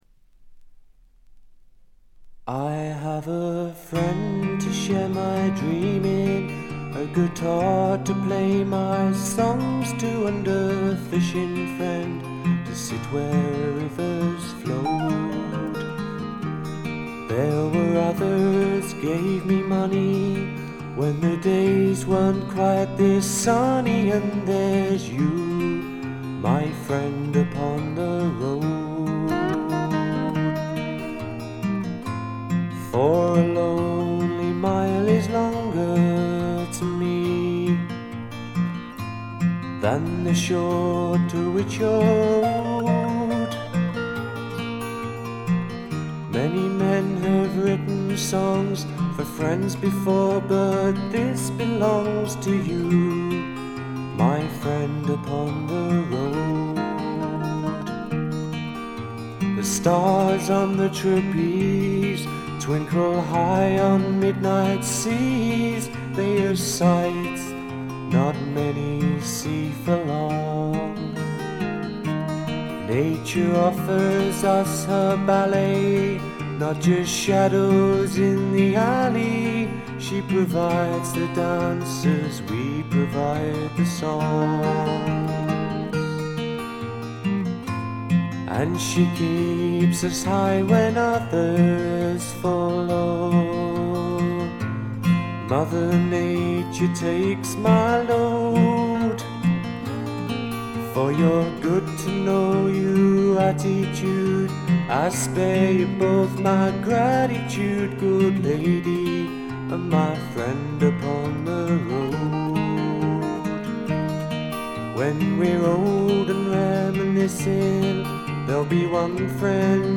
そんなわけでソロになった本作ではまさにマイルドでジェントルなフォーク／フォーク・ロック路線が満開です。
試聴曲は現品からの取り込み音源です。